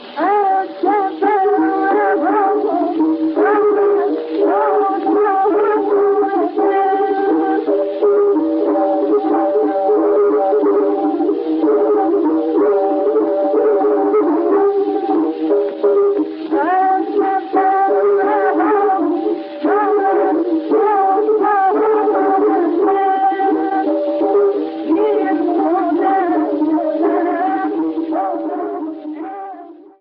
[14] Figures 19–22 are transcriptions of tombak lines from recordings of older styles.
Figure 19. 3/4 meter groove ostinato, from a 1914 expedition to Tbilisi, performed by Abdullah Davami.